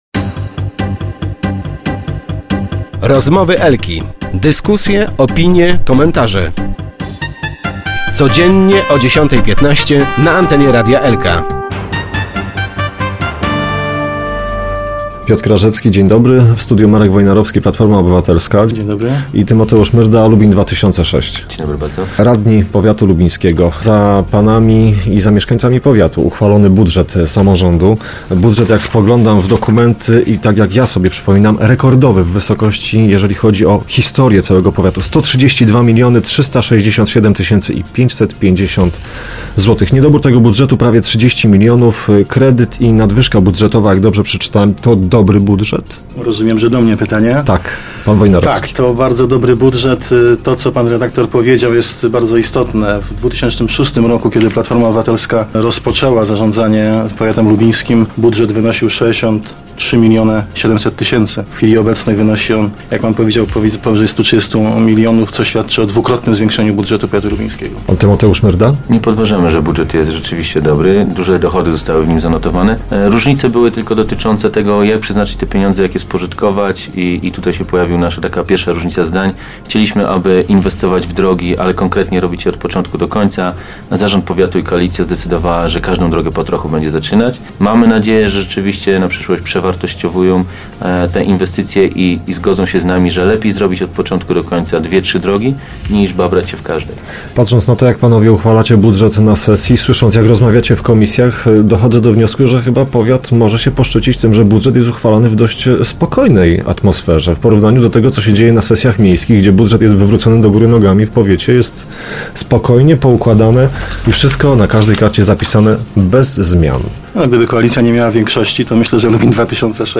Lubin. Nie będzie nowej koalicji w powiecie, ale powiatowi radni z koalicji PO-PiS chwalą sobie współpracę z opozycyjnym Lubin 2006. Marek Wojnarowski szef klubu PO w powiecie w rozmowie z Tymoteuszem Myrdą z Lubin 2006, w porannych rozmowach Radia Elka przyznał że spory dotyczą konkretnych zagadnień i są merytoryczne.
Samorządowcy na naszej antenie przytaczali argumenty dlaczego w takiej a ni innej kolejności powinny być modernizowane powiatowe drogi na terenie Lubin.